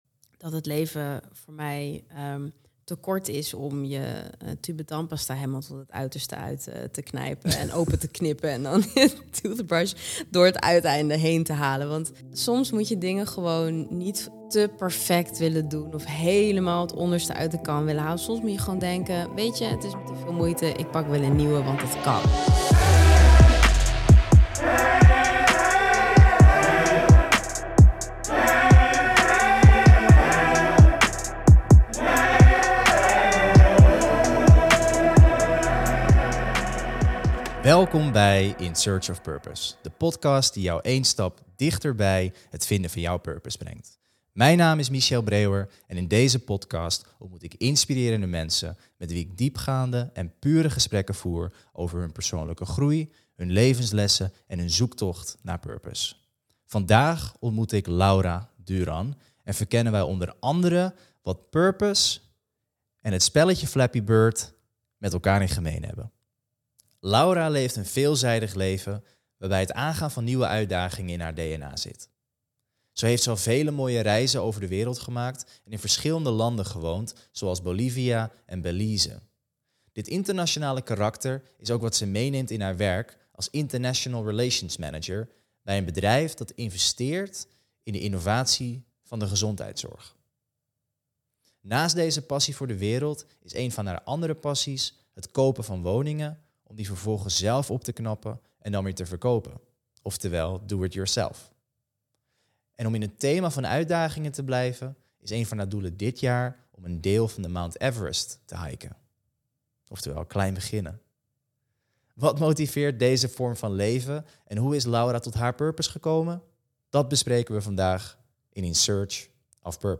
In Search of Purpose, is de podcast die luisteraars één stap dichter bij het vinden van hun purpose brengt door middel van diepgaande en pure gesprekken met inspirerende mensen over hun levenslessen, persoonlijke groei, en zoektocht naar purpose.